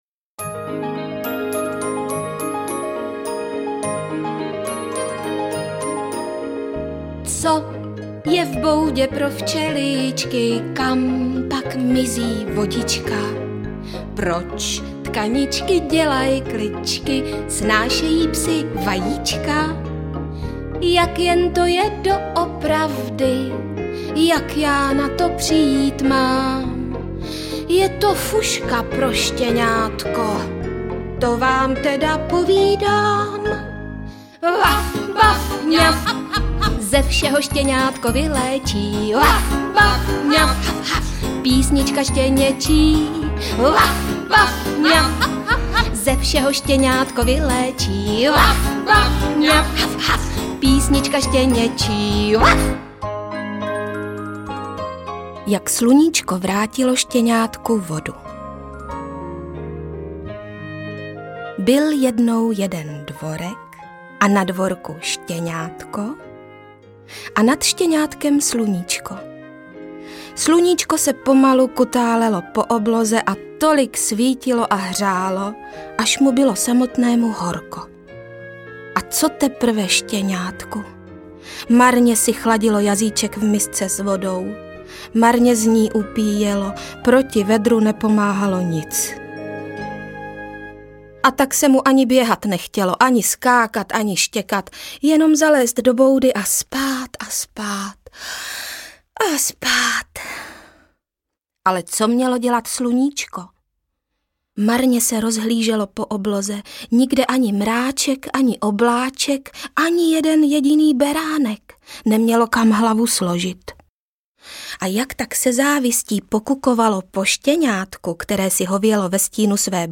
Interpret:  Libuše Šafránková
Rozhodli jsme se shromáždit nahrávky pohádek, které načetla Libuše Šafránková pro Supraphon, v tomto celku, aby v různorodosti textů mohl ještě více vyniknout něžný, milý, srdečný hlas a přirozený herecký výraz oblíbené české interpretky, slavící významné životní jubileum.
AudioKniha ke stažení, 43 x mp3, délka 4 hod. 6 min., velikost 223,3 MB, česky